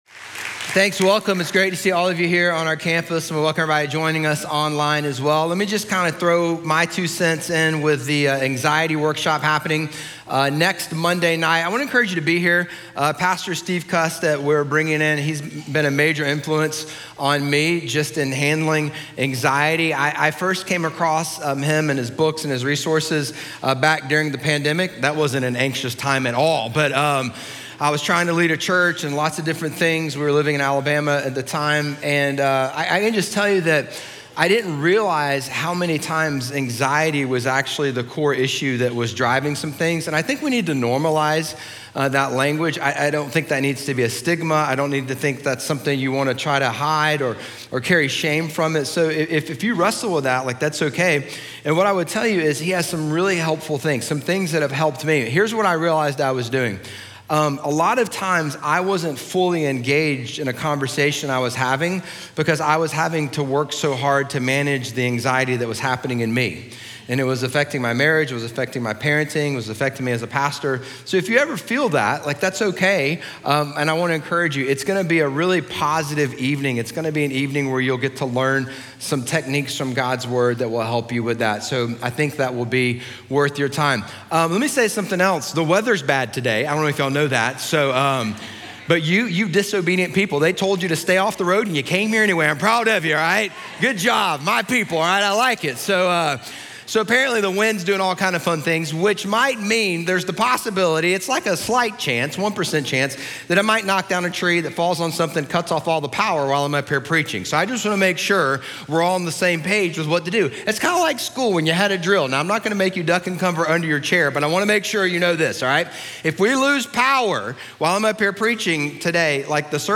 Sermon Series Podcasts
Messages from NewHope Church in Durham, NC.